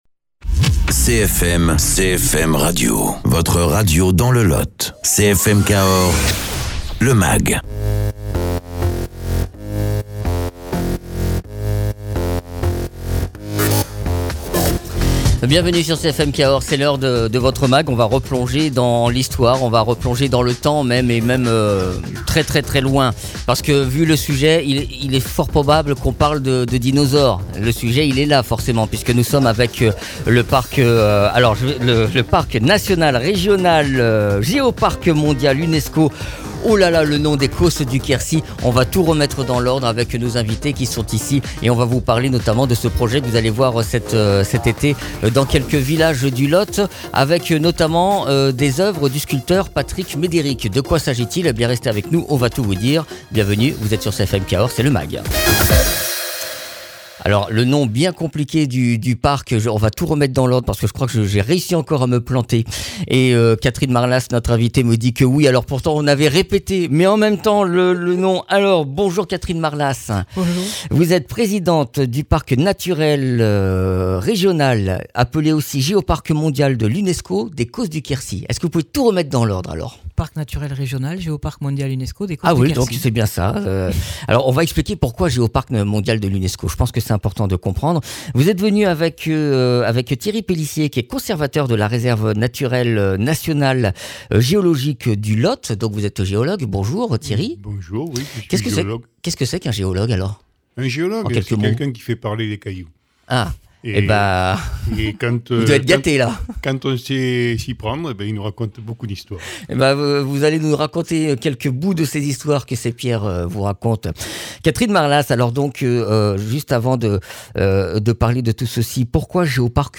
Invité(s) : Catherine Marlas, Présidente du Parc naturel régional Géoparc mondial UNESCO des Causses du Quercy.